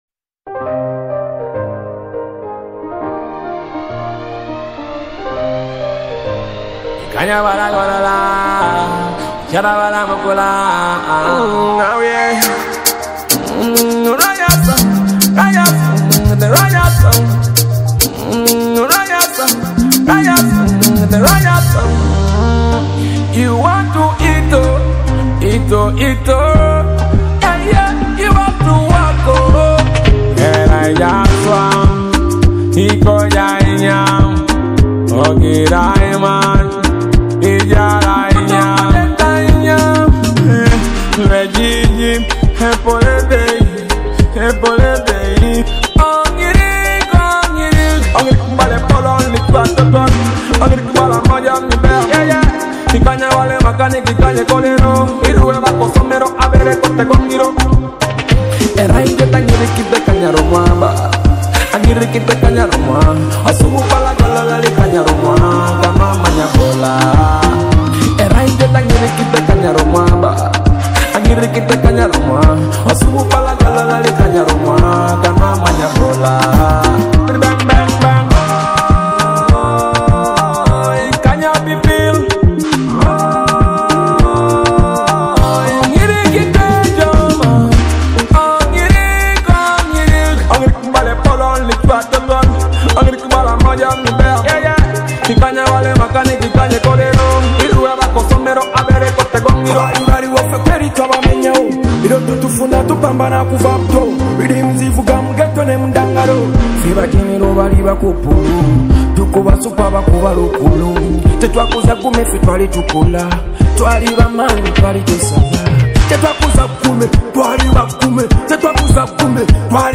brings an uplifting vibe that will inspire and energize you